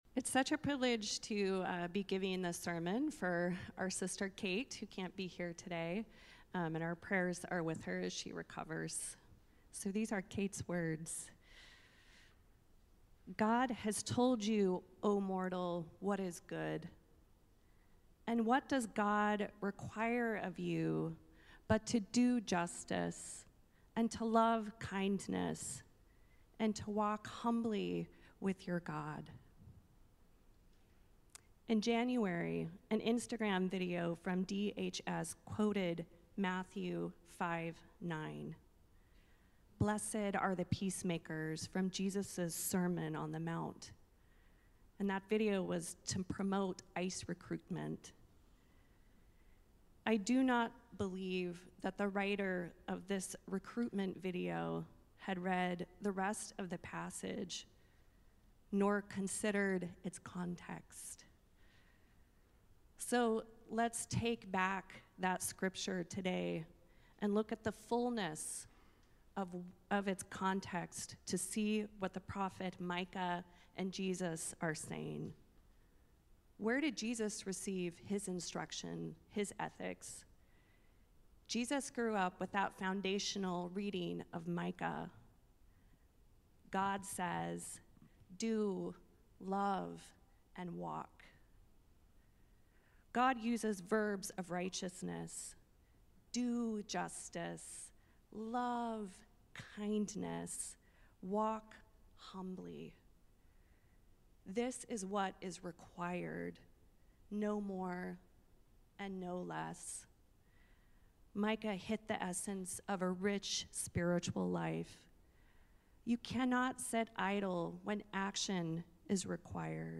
Sermons | Grace Episcopal Church